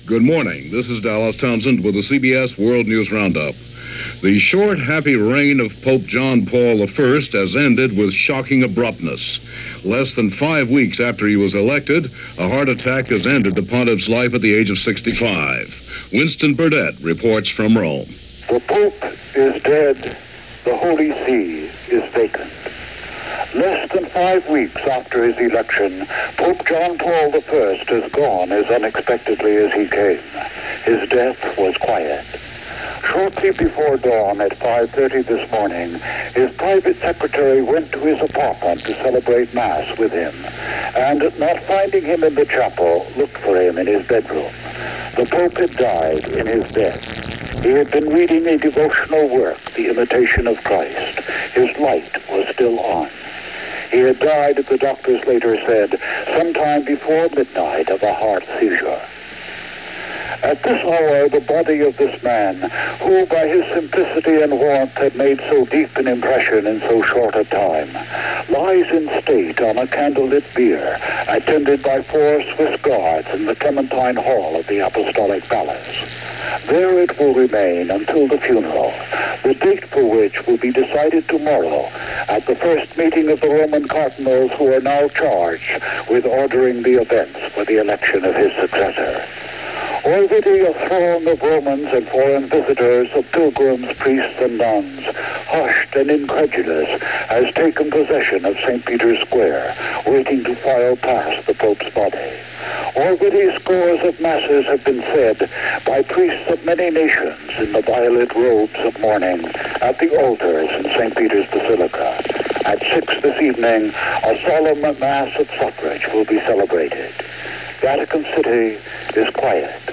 September 29, 1978 – CBS World News Roundup – Gordon Skene Sound Collection –